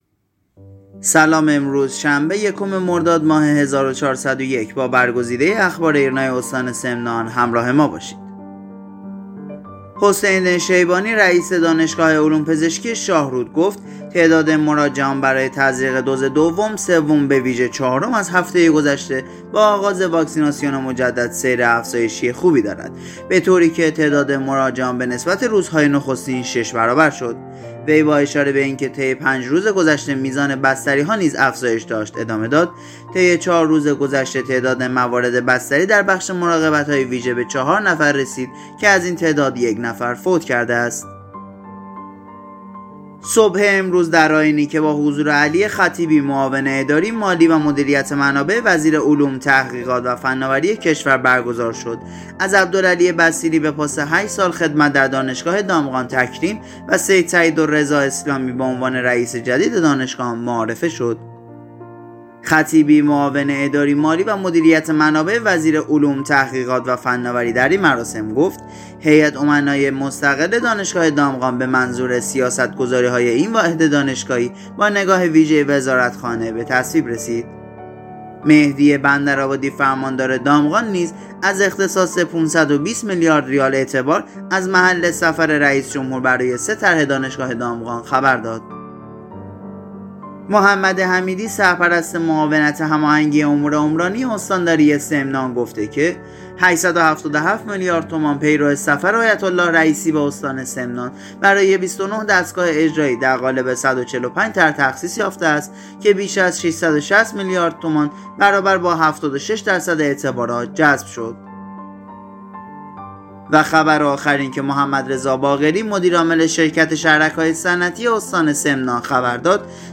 صوت | اخبار شبانگاهی یکم مرداد استان سمنان